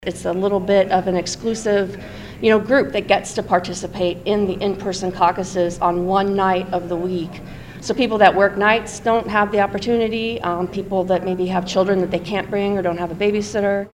Representative Amy Nielsen, a Democrat from North Liberty, says her party is looking to expand access to the Caucuses.